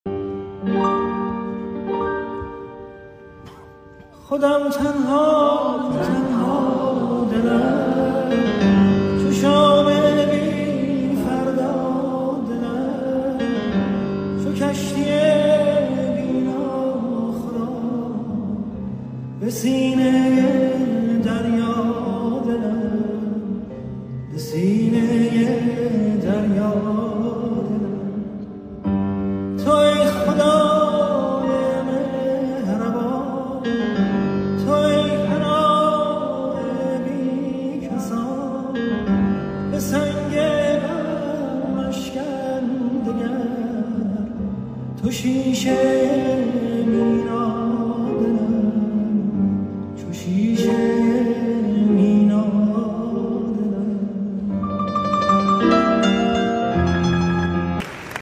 آهنگ سنتی